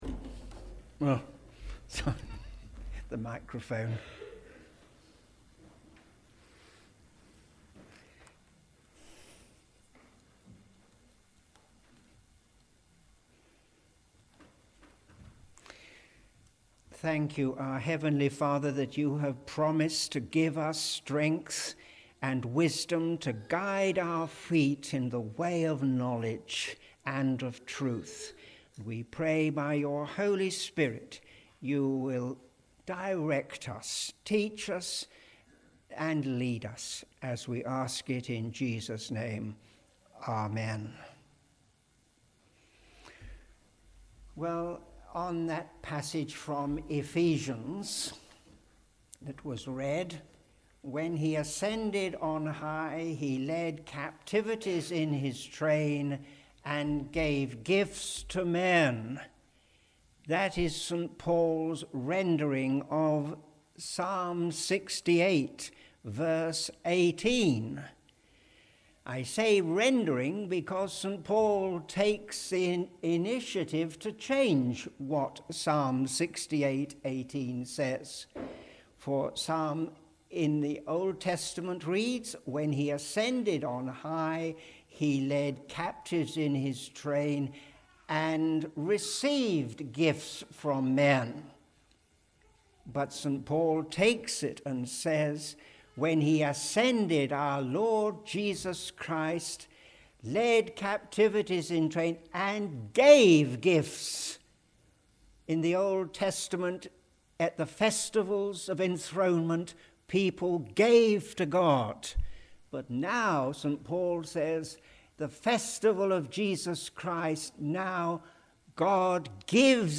A sermon delivered by visiting preacher